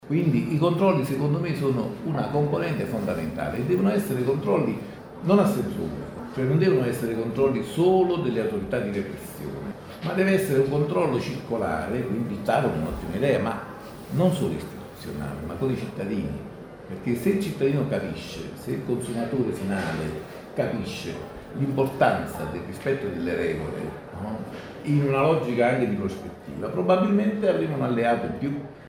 Cultura Il senso della guerra – a Ravenna Festival, in scena il Canto del Divino in un’esperienza di teatro di comunità.